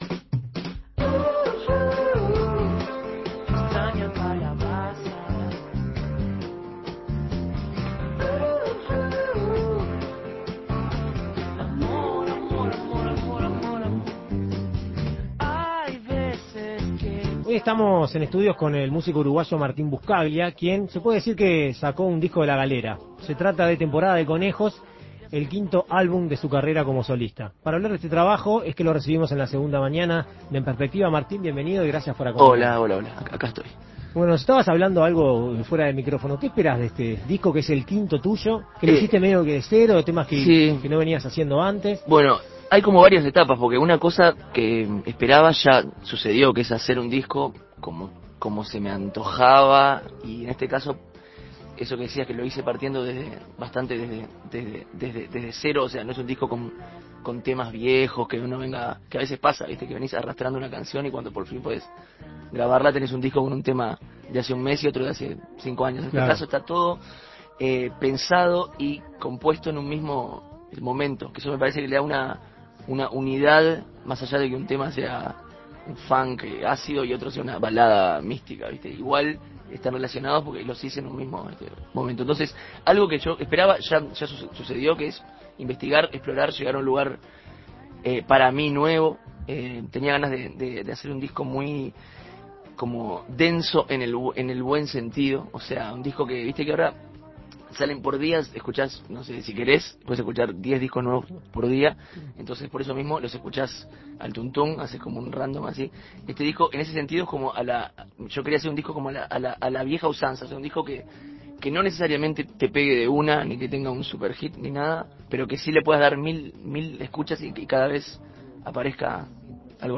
Este jueves a las 20 horas, Martín Buscaglia y sus Bochamakers cantarán canciones del disco Temporada de Conejos, el quinto trabajo de su carrera como solista que salió a la luz el mes pasado. Escuche la entrevista con En Perspectiva Segunda Mañana.